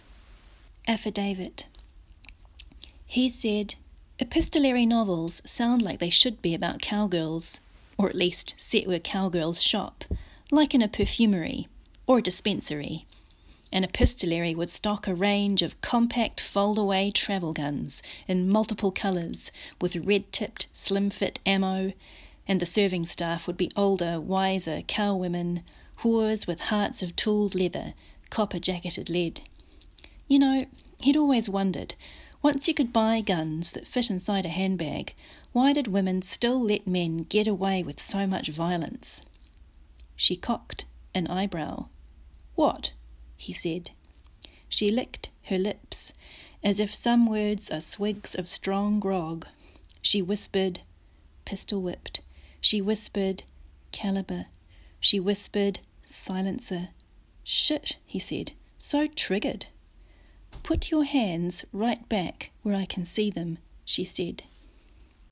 Poetry Shelf audio spot